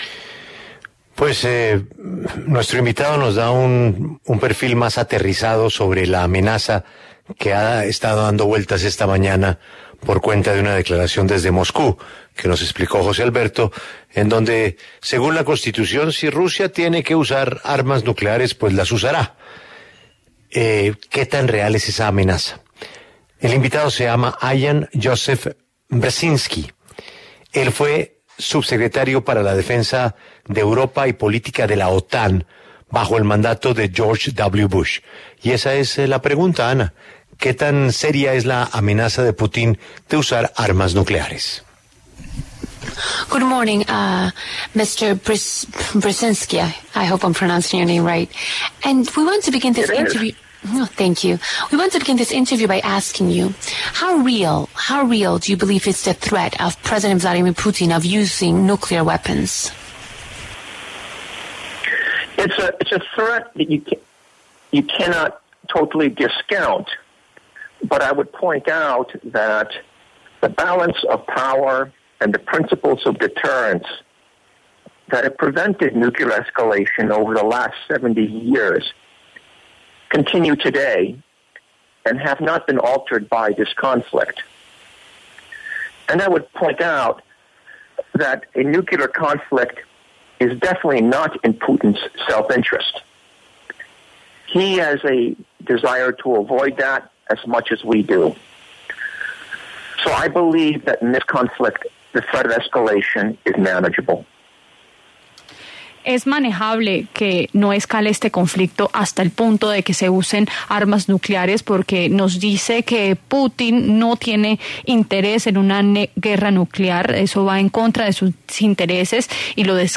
Ian Joseph Brzezinski, ex subsecretario adjunto de Defensa para Europa y Política de la OTAN, habló en La W sobre una escalada nuclear en el conflicto Rusia y Ucrania.